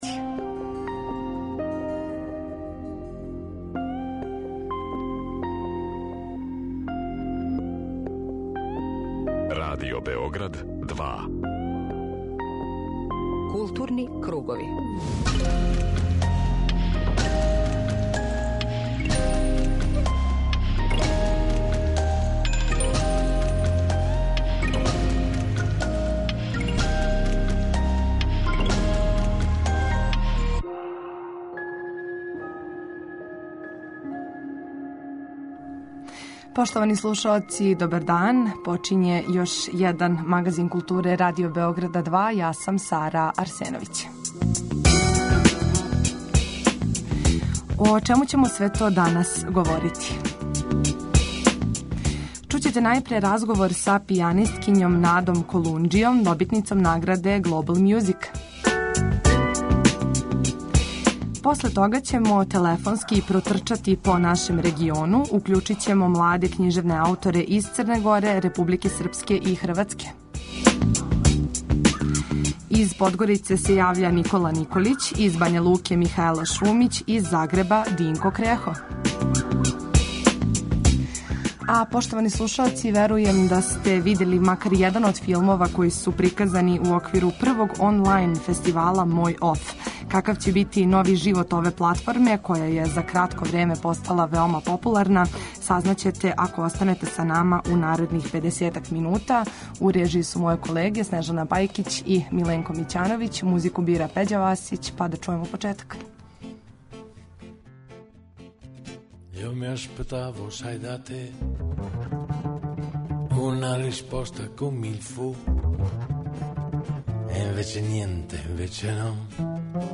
У емисији Културни кругови данас ћемо чути младе писце из региона.
преузми : 19.51 MB Културни кругови Autor: Група аутора Централна културно-уметничка емисија Радио Београда 2.